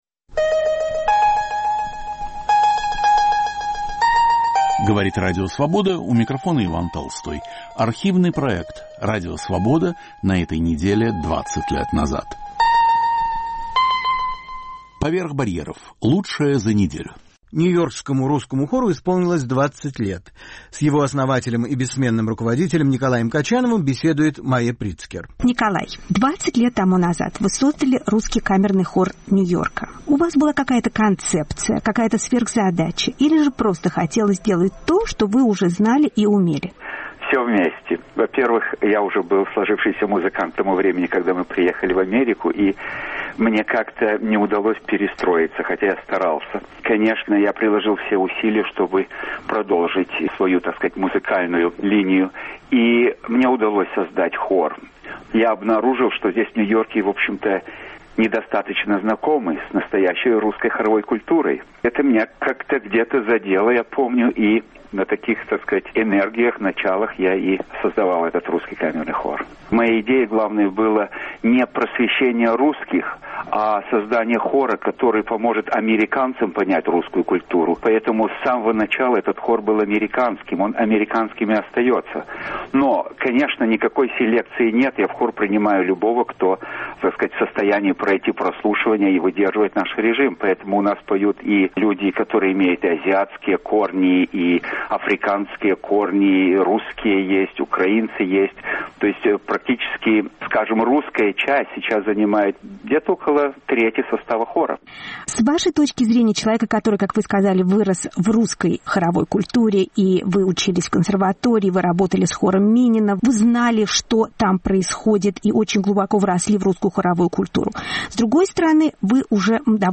Памяти писателя Гая Давенпорта. Редактор и ведущий Алексей Цветков.